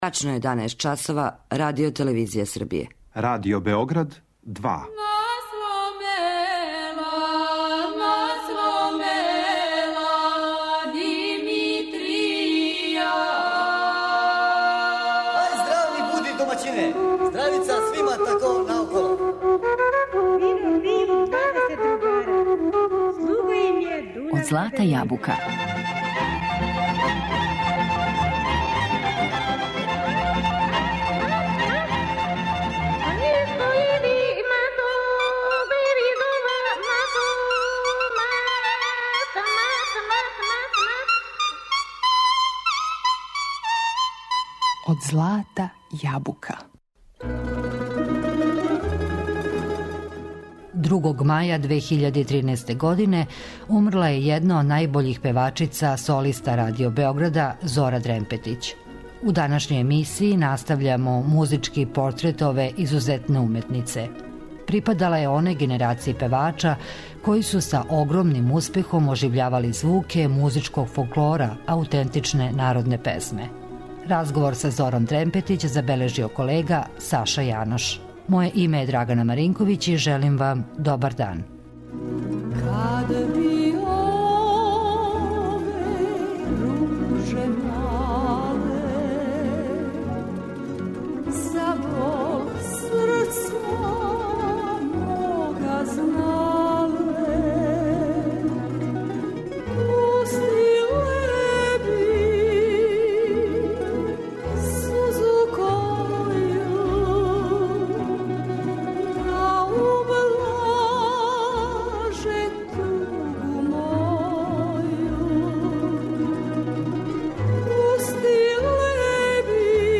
У две емисије представљамо музички портрет уметнице Зоре Дремпетић.